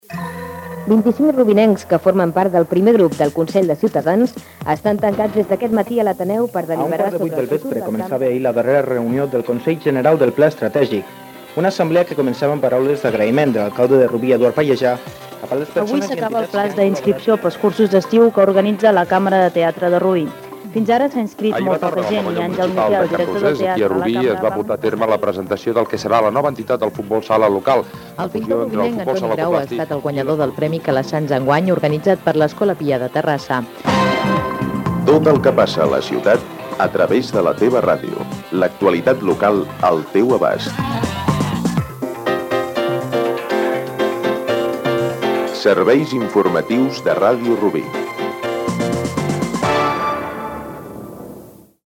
Promoció dels Serveis informatius de Ràdio Rubí.